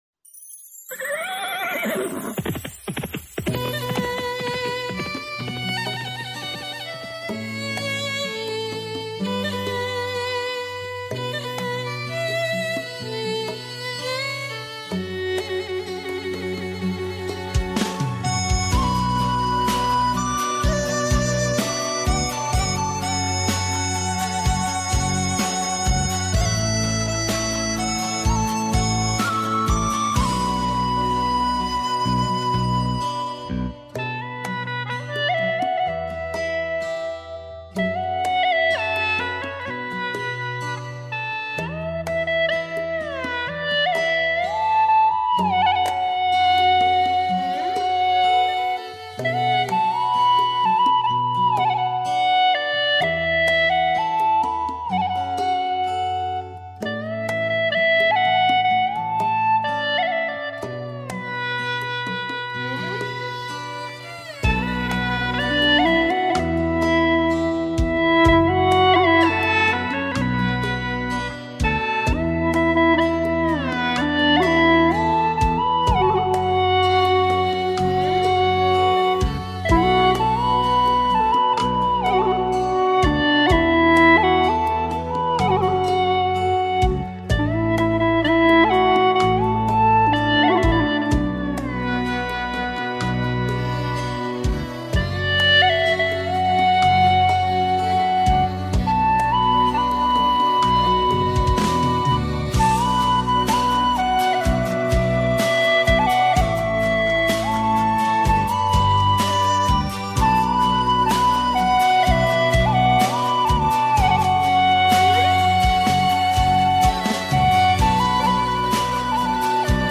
调式 : D调 曲类 : 流行